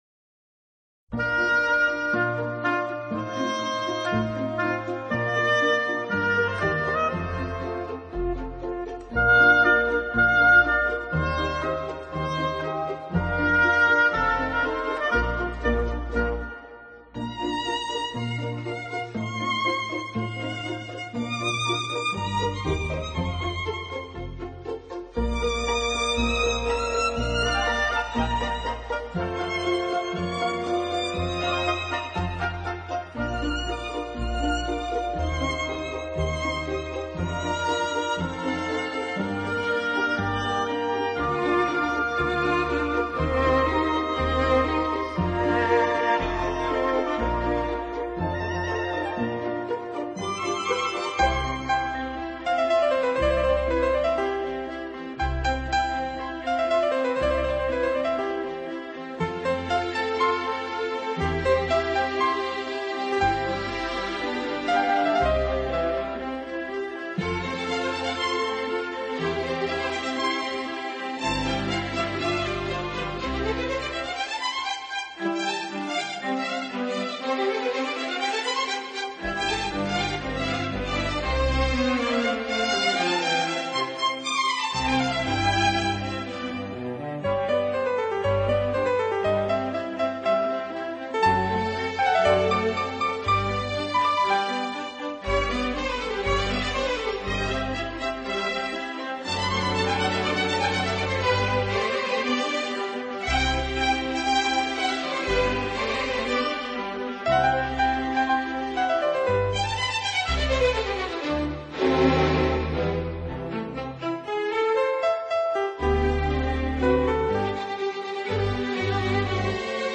【轻音乐专辑】
音乐类型：跨界融合 fusion
音乐风格：Neo Classical,室内乐